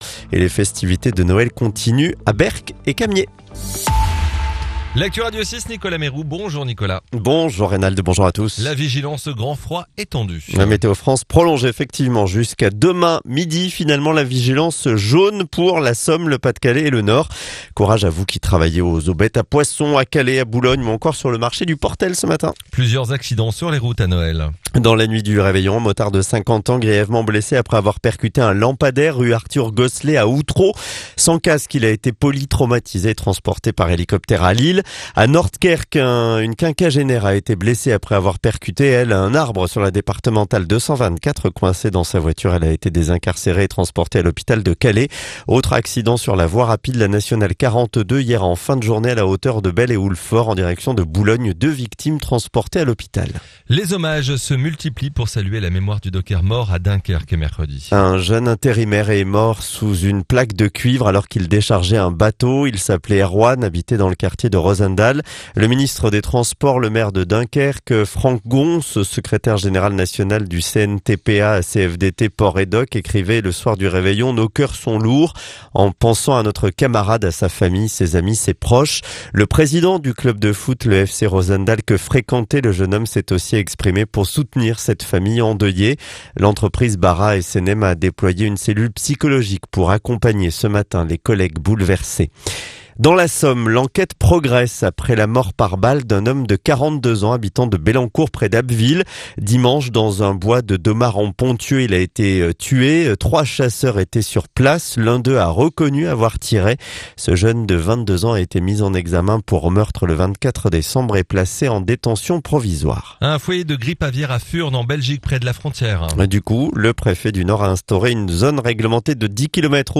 Le journal du vendredi 26 décembre